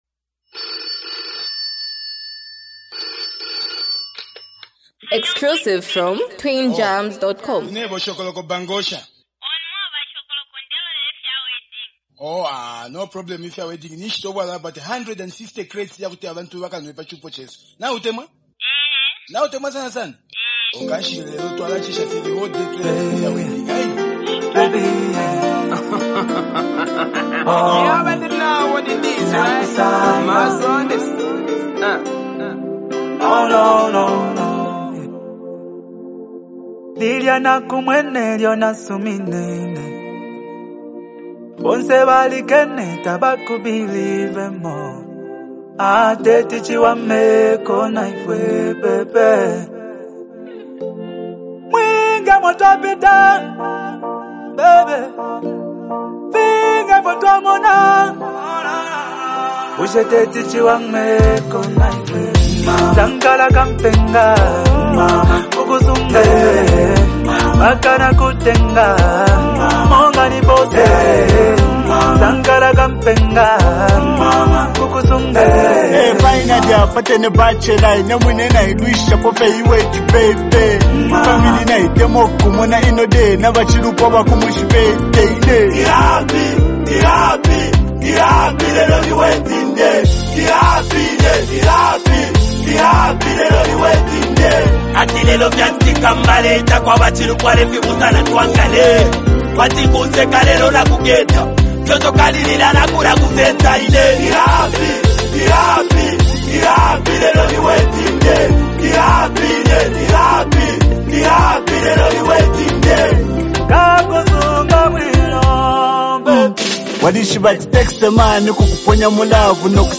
Highly multi talented act and super creative duo rappers